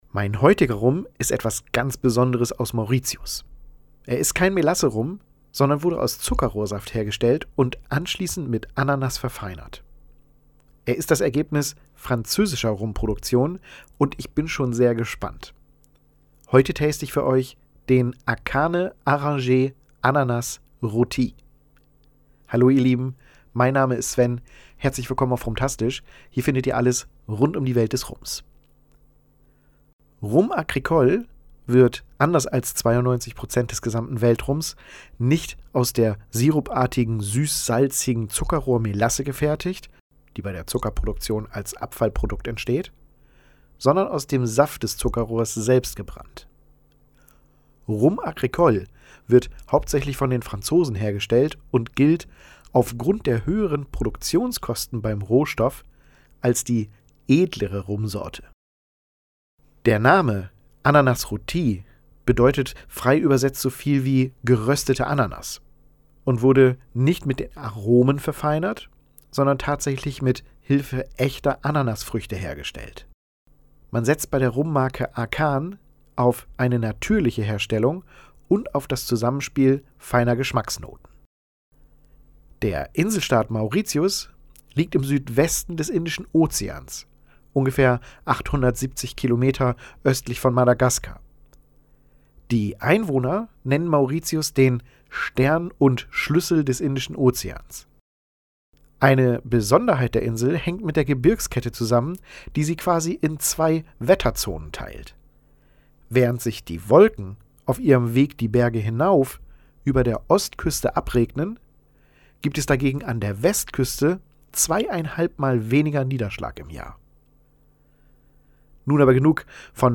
Hier findest Du das Tasting-Video dieses Rums als Audio-Podcast aufbereitet und oben kannst Du Dir den Blogbeitrag dieser Seite als „Blog-RumPod“ anhören.